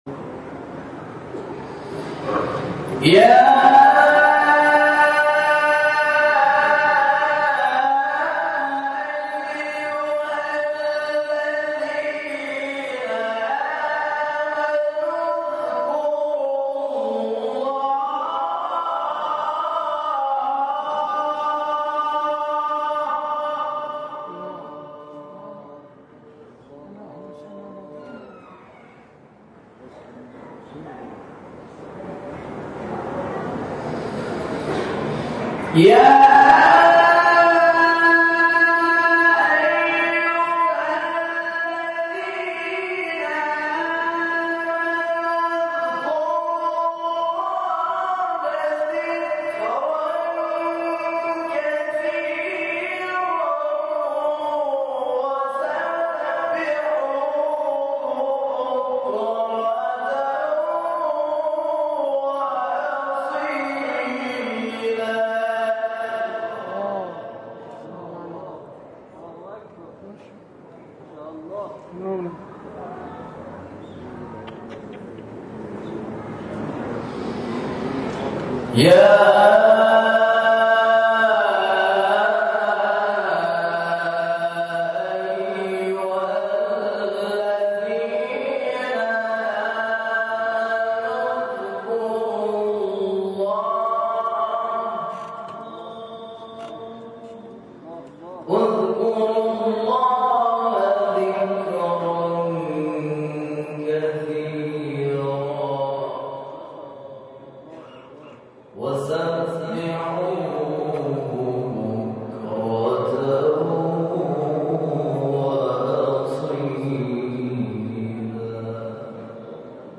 تلاوت آیاتی از سوره احزاب
در مسجد المهدی(عج) این شهر به تلاوت آیات 41 تا 48 سوره احزاب و 1 تا 9 سوره شمس پرداخته است.
ابتدای این تلاوت موجود نیست.